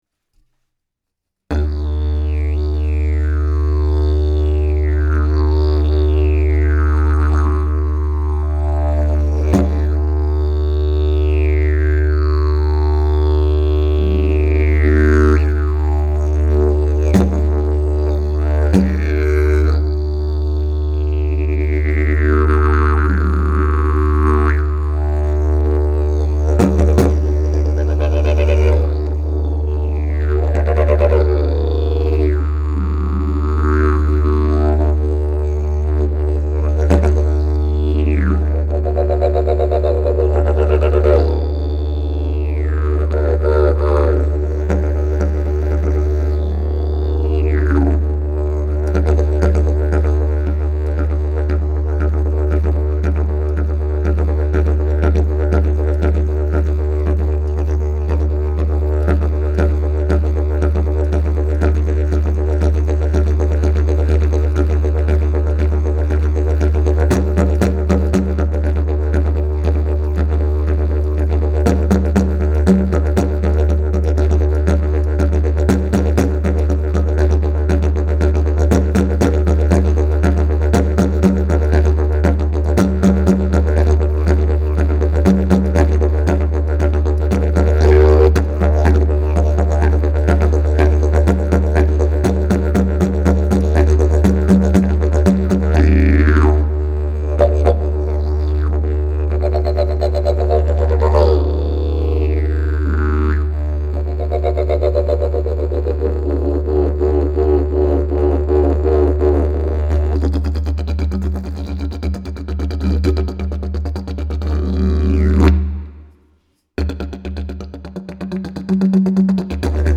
Key: D# Length: 60" Bell: 6.5" Mouthpiece: Canary, Ash Back pressure: Very strong Weight: 3 lbs Skill level: Any Sale pending
Didgeridoo #625 Key: D#